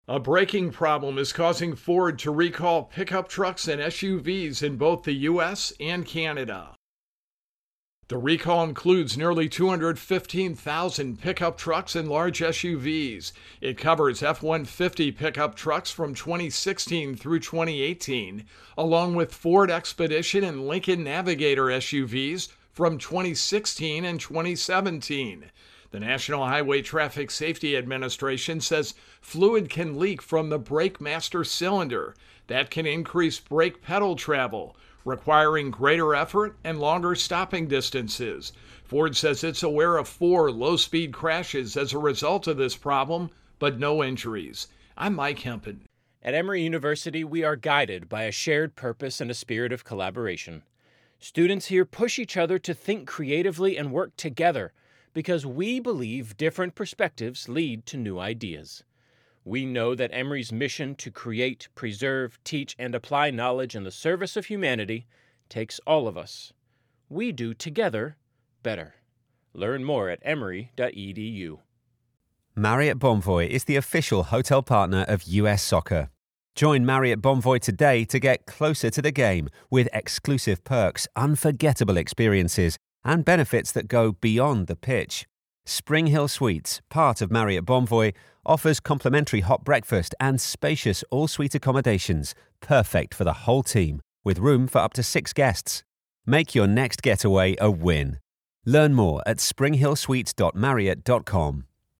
Intro and voicer for Ford Recall.